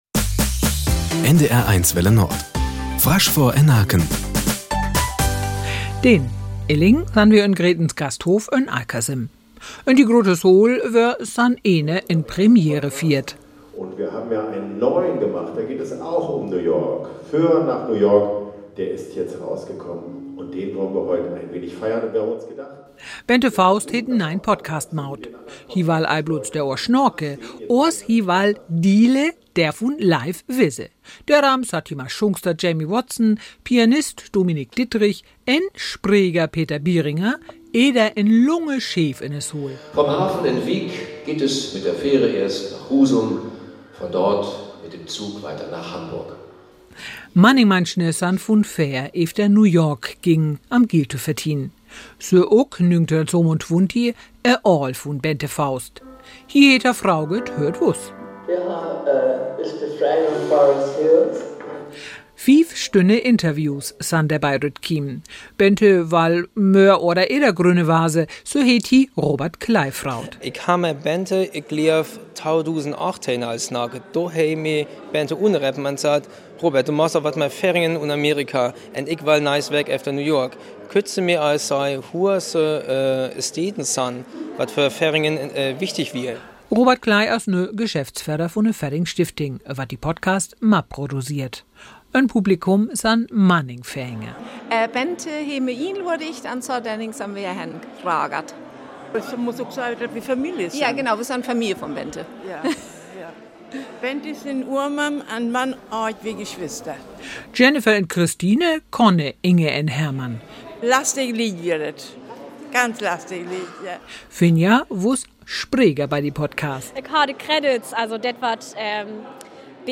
liest vor Publikum in Alkersum